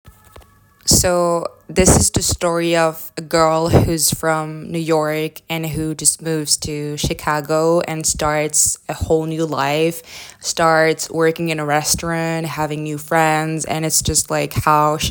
Voix off
15 - 40 ans - Mezzo-soprano